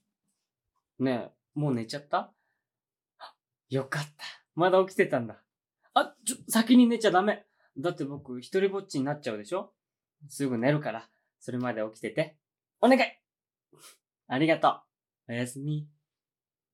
ハル おやすみボイス
ハルおやすみボイス.wav